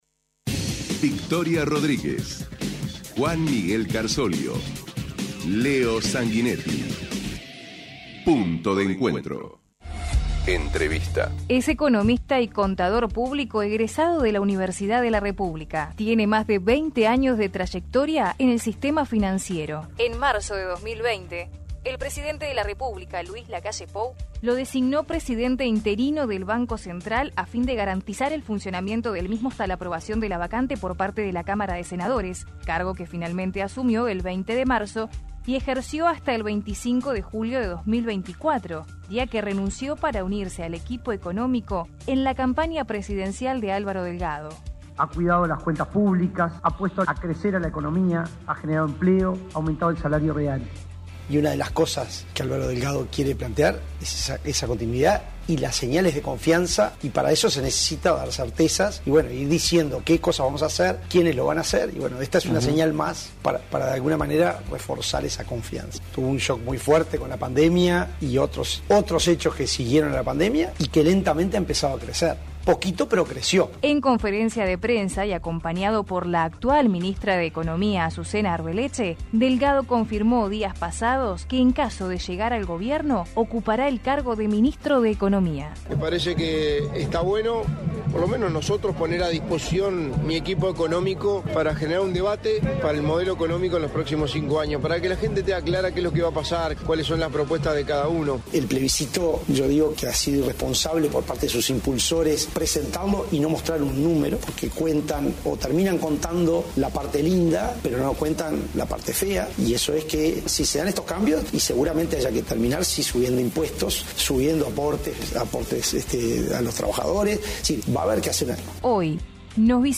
Entrevista a Diego Labat